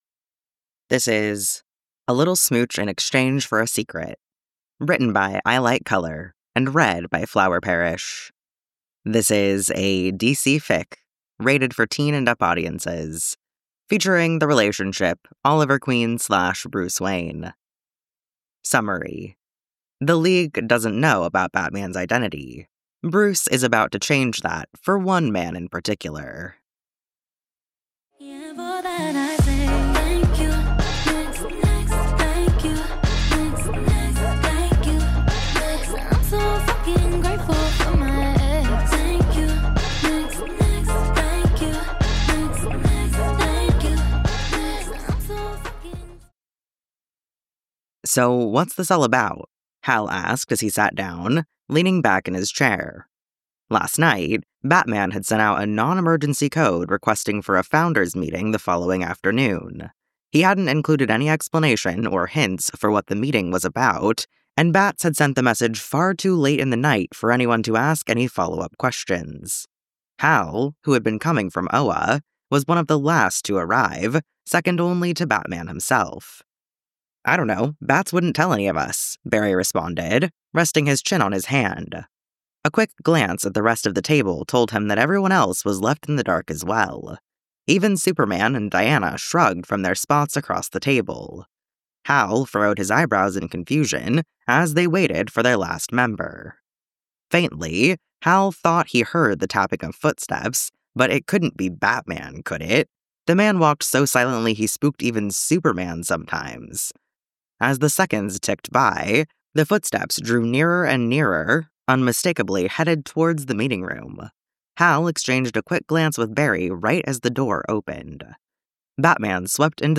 fandom: batman (comics) , justice league , green lantern relationship: bruce wayne/hal jordan info: collaboration|ensemble